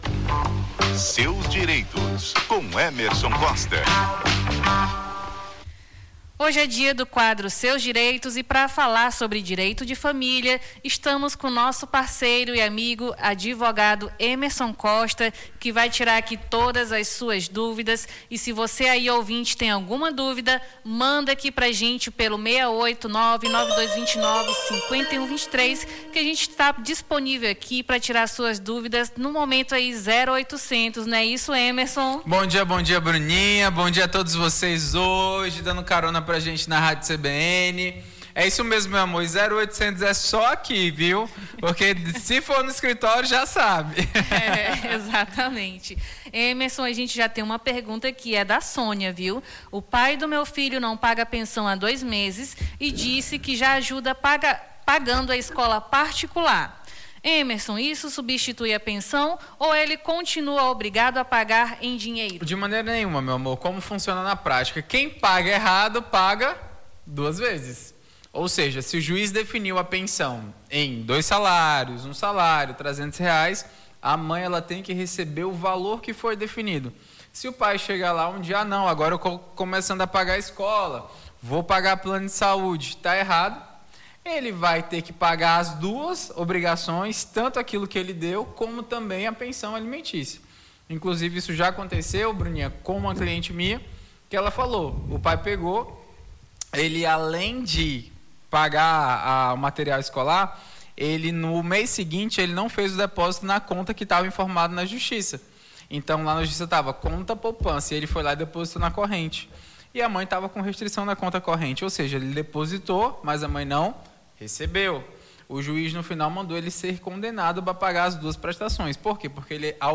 Na manhã desta sexta-feira, 24, conversamos com o advogado e especialista em direito de família